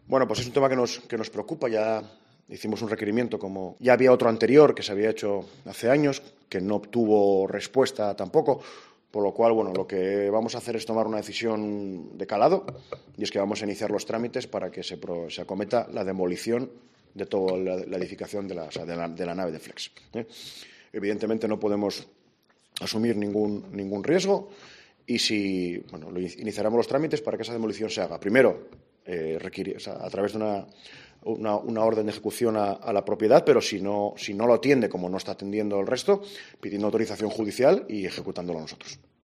El portavoz del gobierno de Gijón, Jesús Martínez Salvador, sobre el derribo de la fábrica de Flex